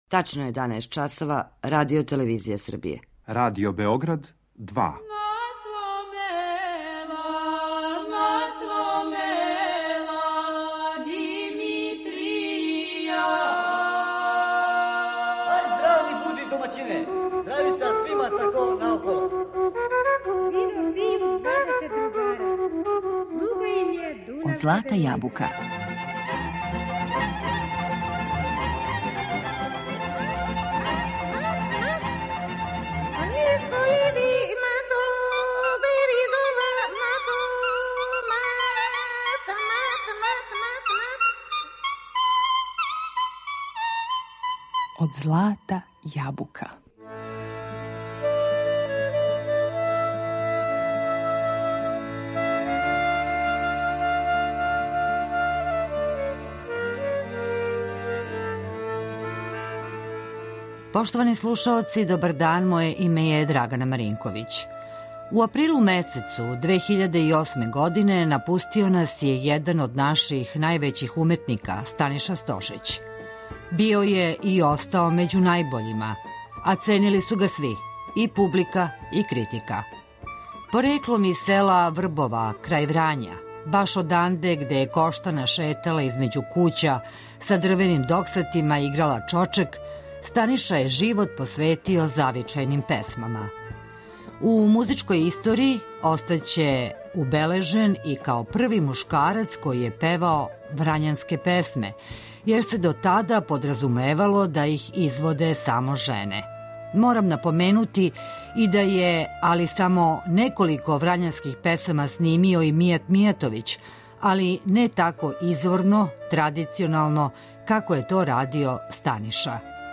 Mnoge stare vranjske pesme stigle su u Radio Beograd upravo zahvaljujući Staniši.